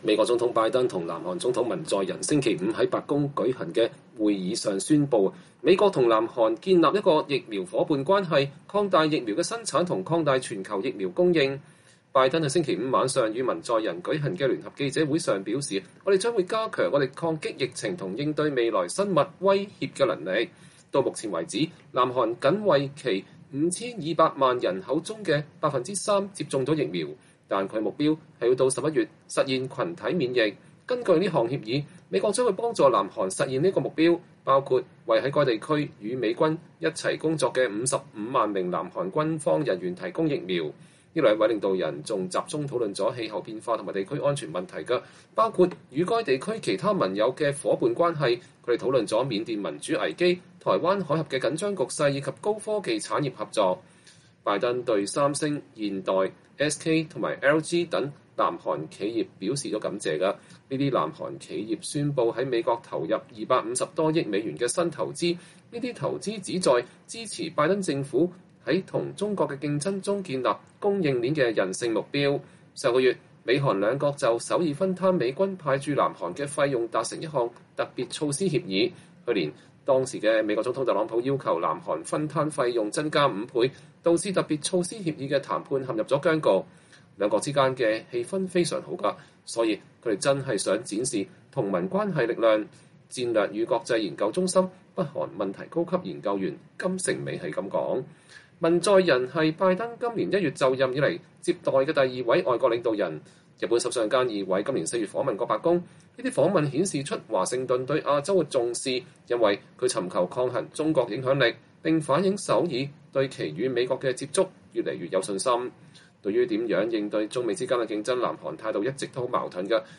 2021年5月21日，美國總統拜登和南韓總統文在寅在白宮舉行了一天的會談後舉行了聯合記者會。